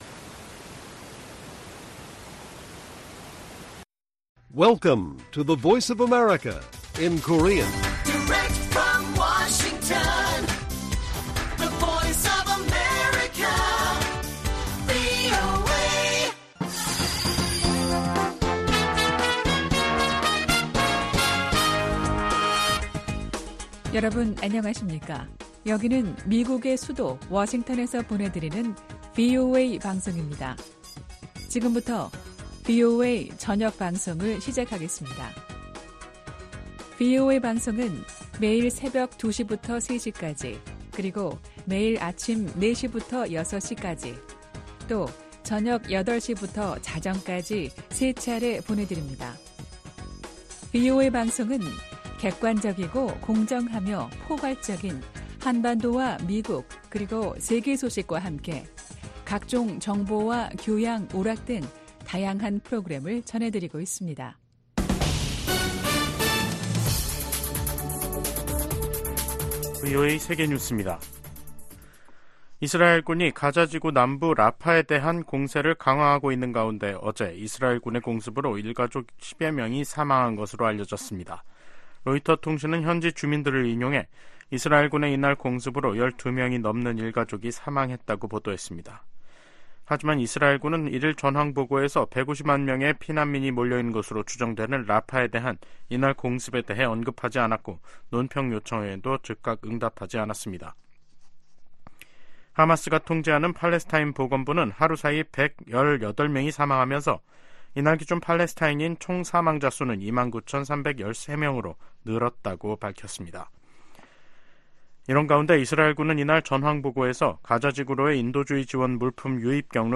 VOA 한국어 간판 뉴스 프로그램 '뉴스 투데이', 2024년 2월 22일 1부 방송입니다. 미 국무부가 북한 대량살상무기·탄도미사일에 사용될 수 있는 민감한 품목과 기술 획득을 막는데 모든 노력을 기울일 것이라고 밝혔습니다. 미 하원에서 우크라이나 전쟁 발발 2주년을 맞아 러시아·북한·중국·이란 규탄 결의안이 발의됐습니다. 한국 정부는 북한과 일본 간 정상회담 관련 접촉 움직임에 관해, 한반도 평화 유지 차원에서 긍정적일 수 있다는 입장을 밝혔습니다.